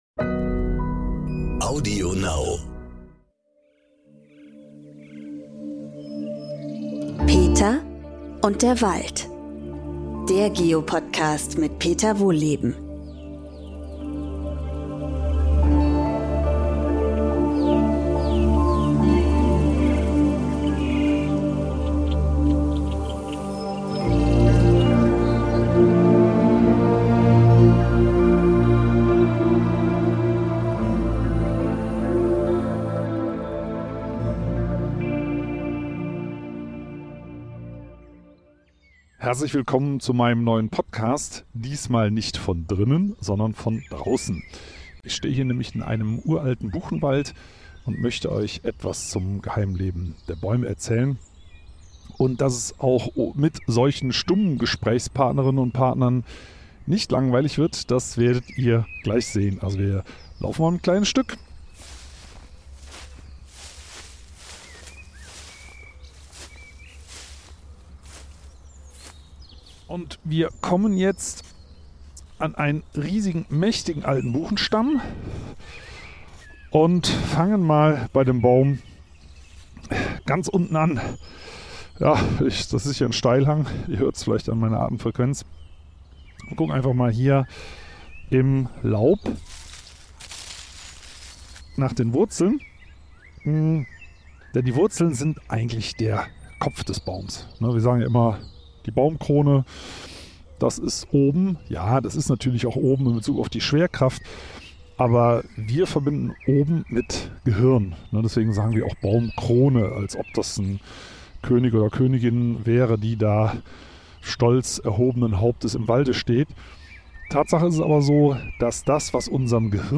Und wie kommt das Wasser eigentlich von oben nach unten?Diesmal gibt’s keinen Podcast von drinnen, sondern Peter Wohlleben nimmt uns mit nach draußen in den Wald und erzählt vom Leben der Bäume.